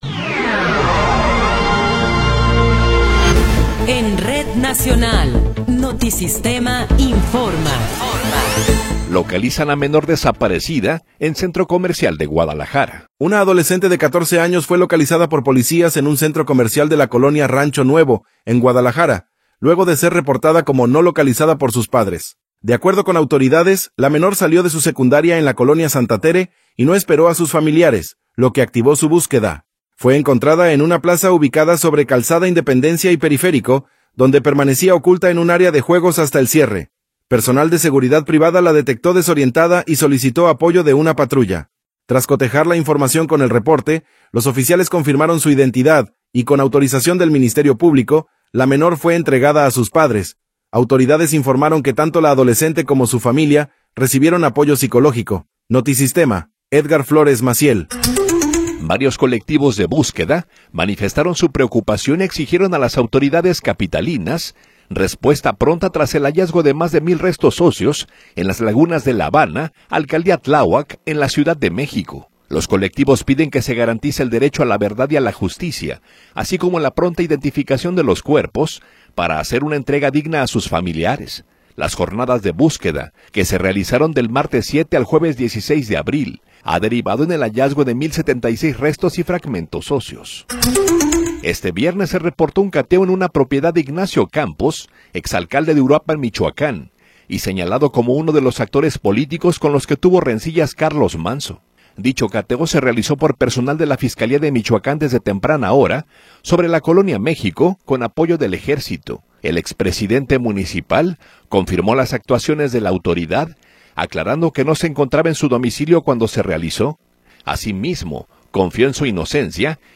Noticiero 11 hrs. – 17 de Abril de 2026
Resumen informativo Notisistema, la mejor y más completa información cada hora en la hora.